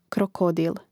krokòdil krokodil
im. m. (GA krokòdila, DL krokòdilu, I krokòdilom; mn. N krokòdili, G krokòdīlā, DLI krokòdilima, A krokòdile)